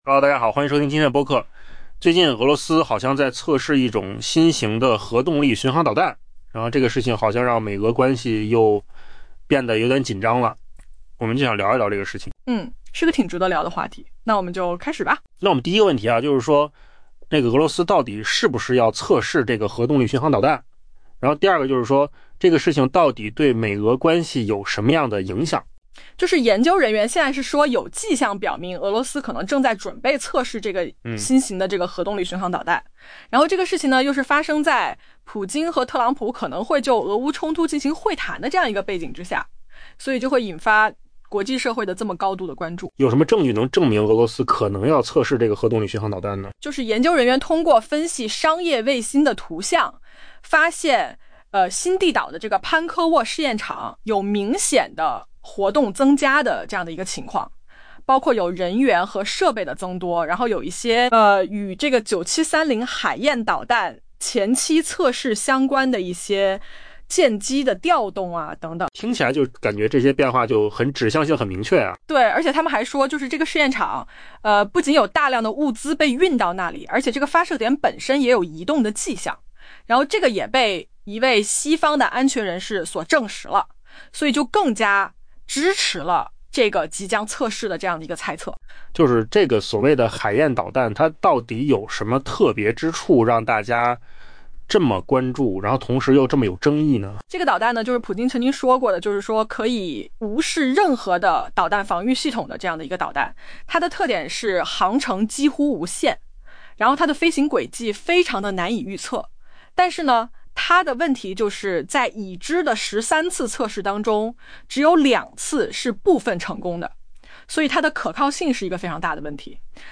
AI播客：换个方式听新闻.
音频由扣子空间生成